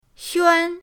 xuan1.mp3